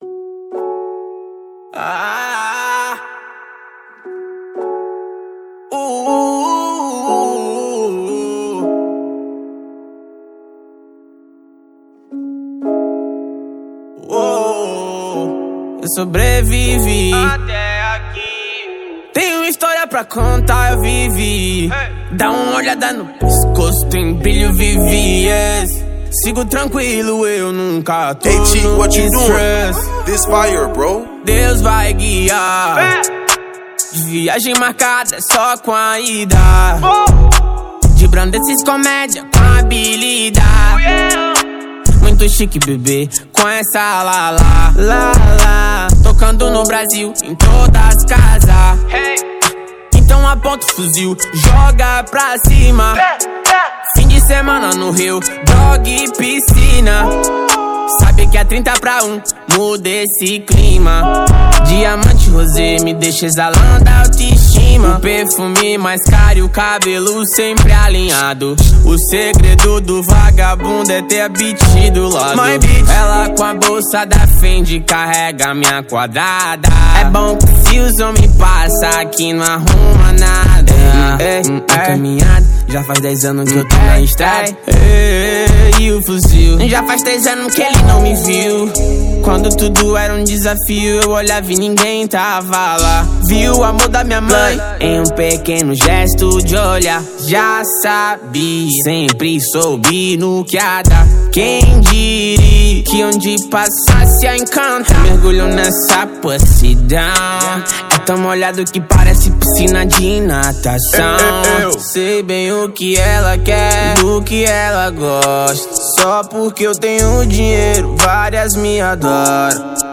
2024-12-20 23:39:43 Gênero: Phonk Views